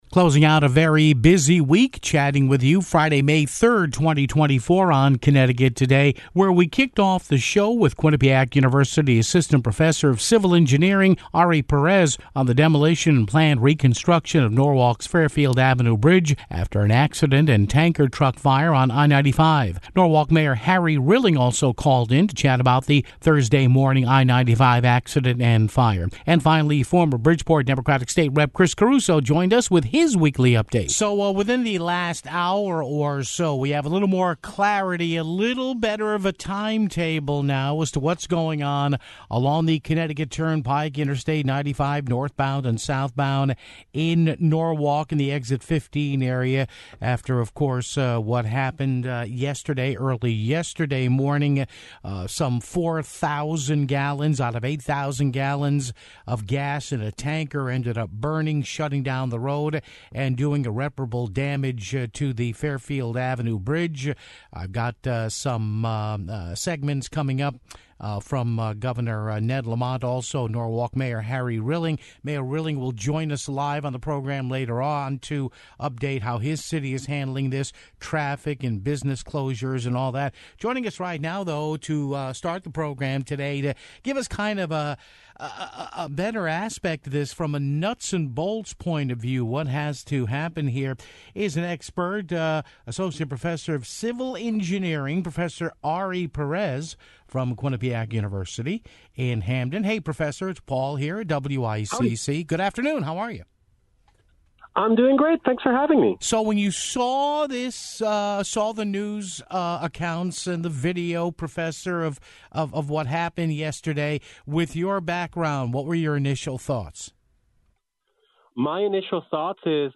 Norwalk Mayor Harry Rilling also called in to chat about Thursday morning's I-95 accident and fire (10:04). Former Bridgeport State Rep. Chris Caruso also joined us with his weekly update (15:15)